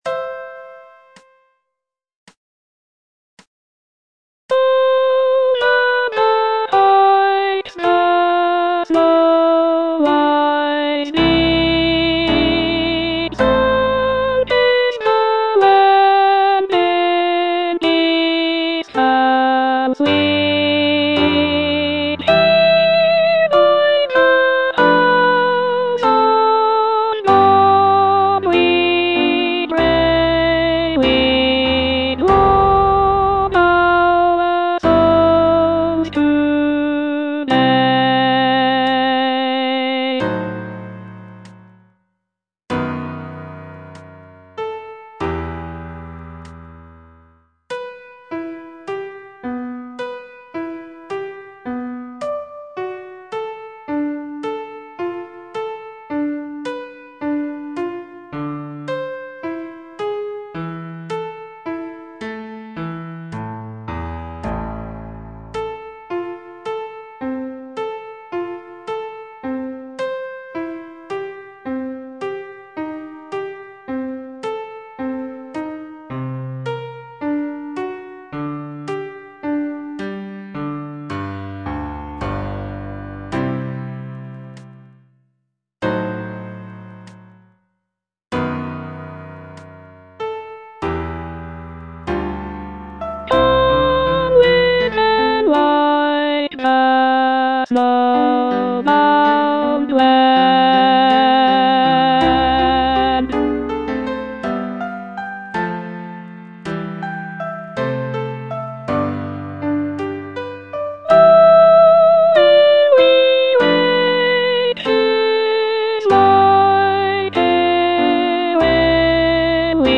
E. ELGAR - FROM THE BAVARIAN HIGHLANDS Aspiration (alto I) (Voice with metronome) Ads stop: auto-stop